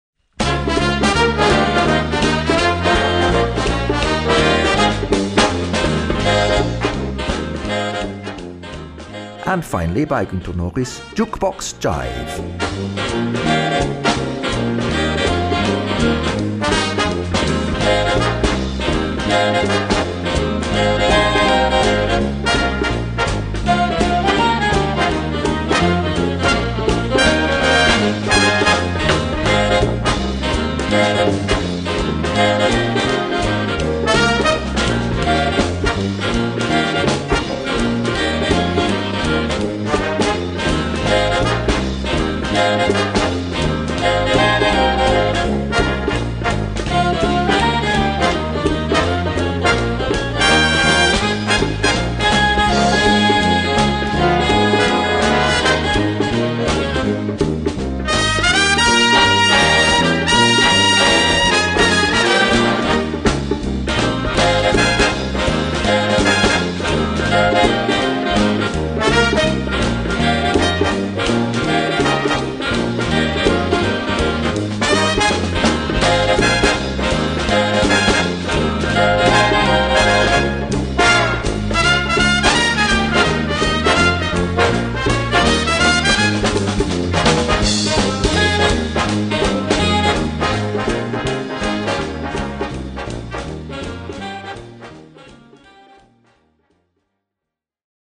Gattung: Konzertante Unterhaltungsmusik
Besetzung: Blasorchester